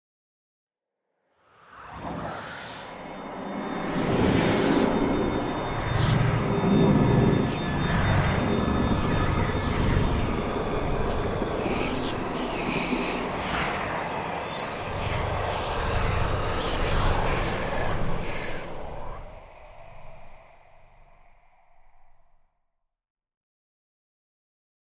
От шепота потусторонних сущностей до зловещих завываний – каждый звук перенесёт вас в мир, где реальность теряет границы.
Звук проклятия наложенного на жертву